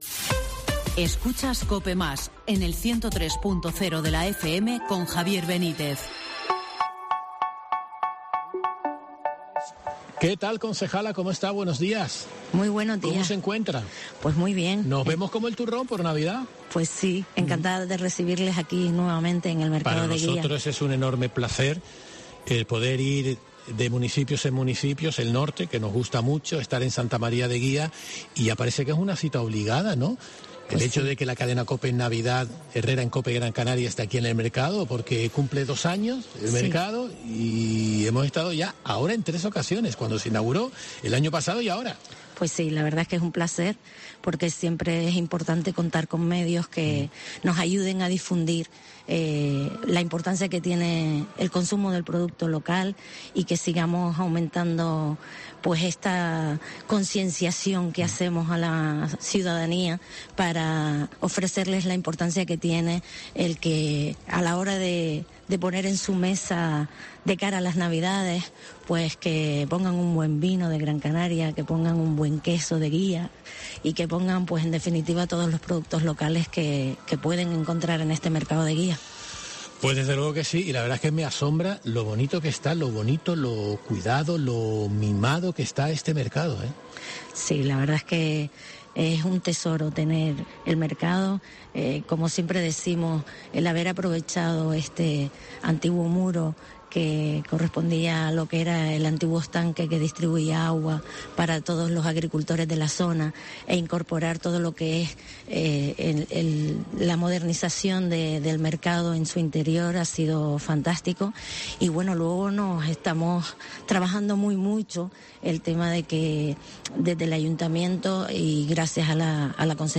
Tere Bolaños, concejala de Mercados de Santa María de Guía
Herrera en COPE Gran Canaria se trasladó al mercado para conocer de primera mano la oferta de productos que ofrece, la concejala del sector primario y mercados, Tere Bolaños, destacó el “tesoro” que supone para el municipio tener un mercado como este apuntando la necesidad de “concienciar” a la población para que “compren productos de la tierra; un buen vino de Gran Canaria, un buen queso de Guía además de otros productos de la isla.”